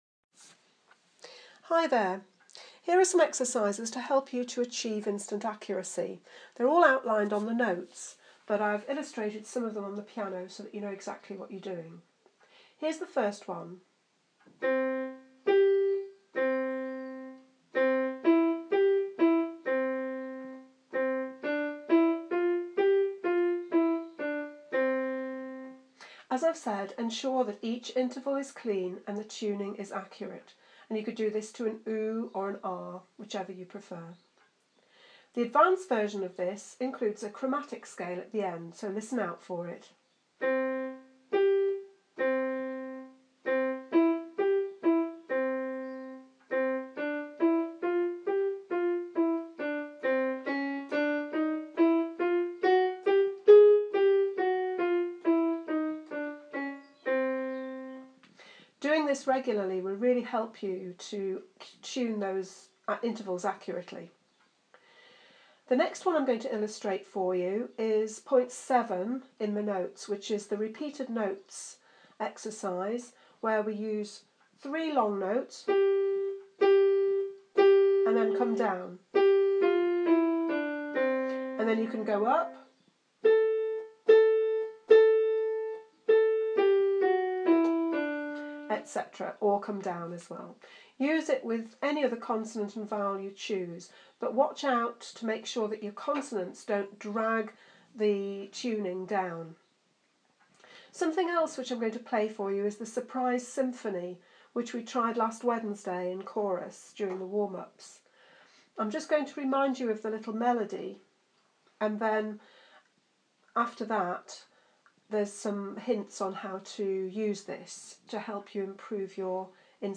Daily Vocal Exercises (2015) | Lace City Chorus
Vocal exercises - instant accuracy (2015).mp3